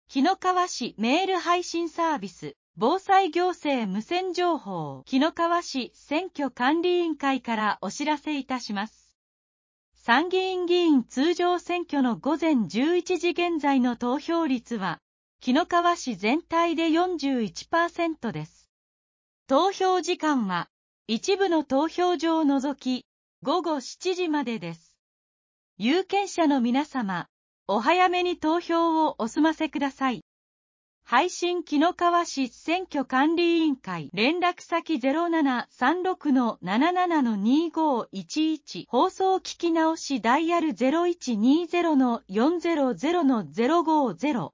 紀の川市メール配信サービス 【防災行政無線情報】 紀の川市選挙管理委員会からお知らせいたします。